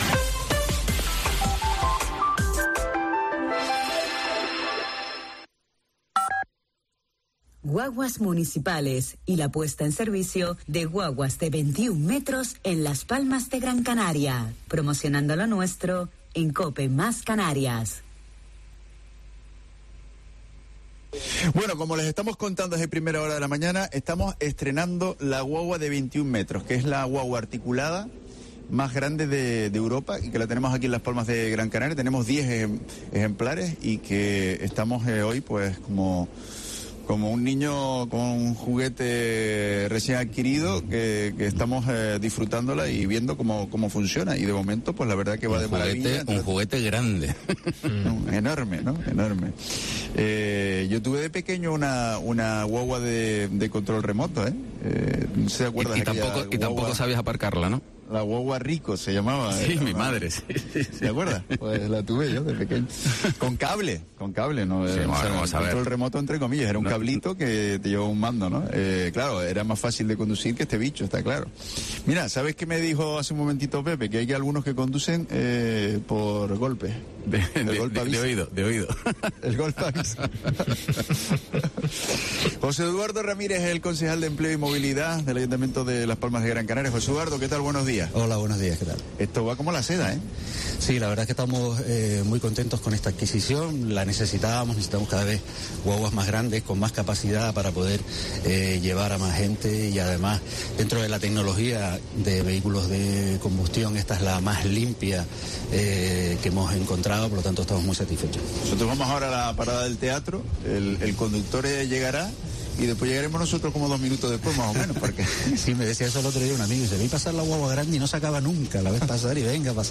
Entrevista a José Eduardo Ramírez, concejal de Movilidad y presidente de Guaguas Municipales